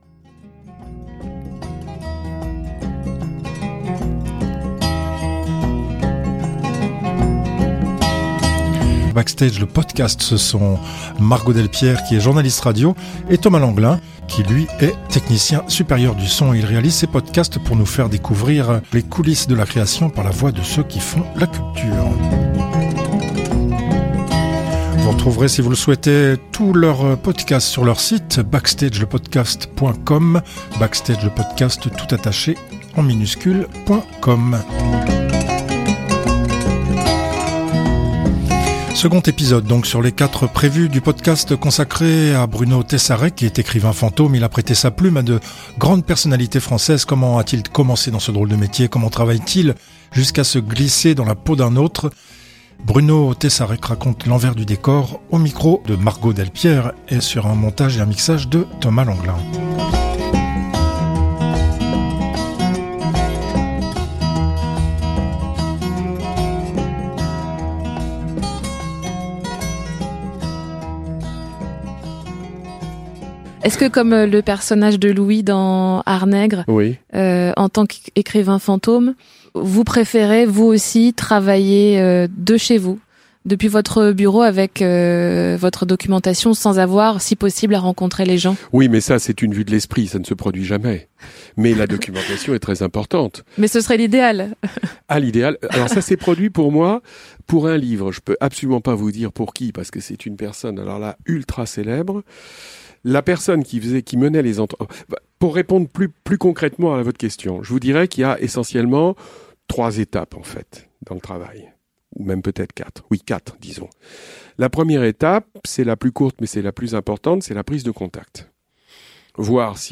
Seconde partie de l'entretien enregistré en octobre 2018. interview